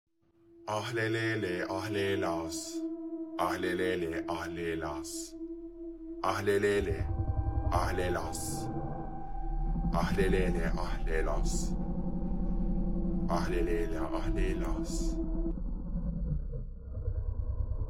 Ahlelele ahlelas meme Sound Effect
Ahlelele-ahlelas-meme-Sound-Effect.mp3